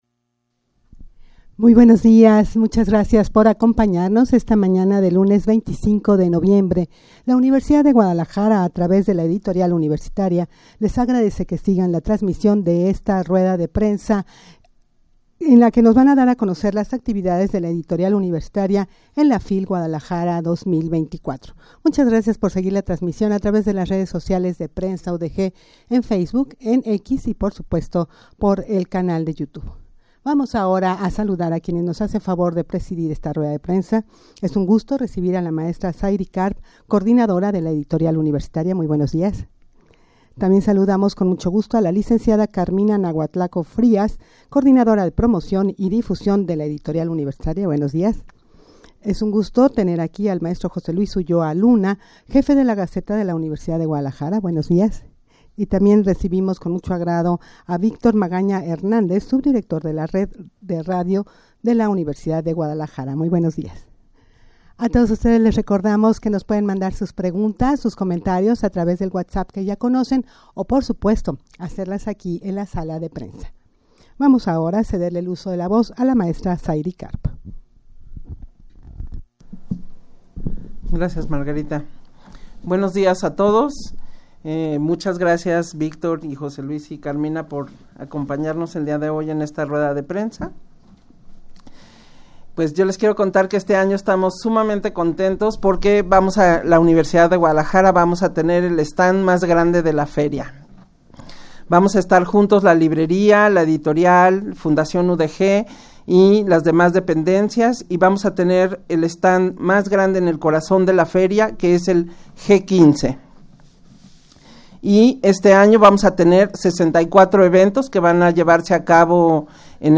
Audio de la Rueda de Prensa
rueda-de-prensa-para-dar-a-conocer-las-actividades-de-la-editorial-universitaria-en-la-fil-guadalajara-2024.mp3